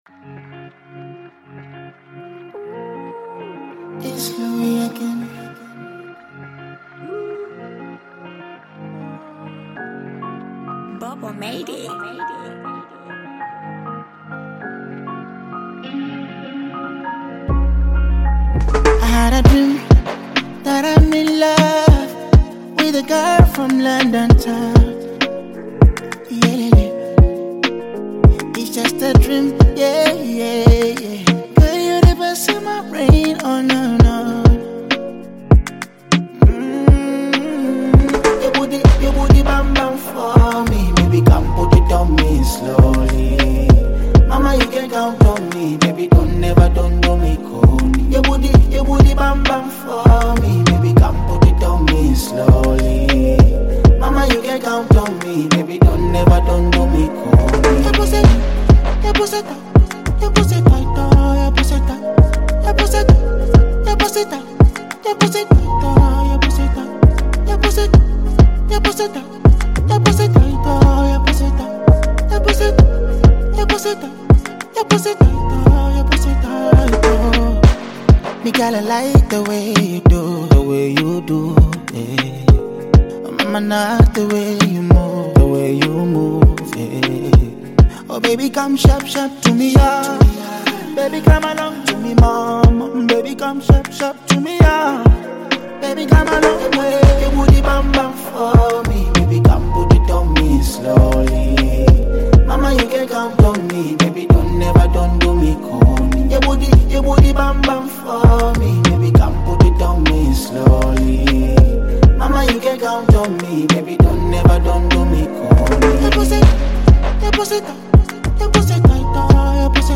Tanzanian Bongo Flava artist, singer and songwriter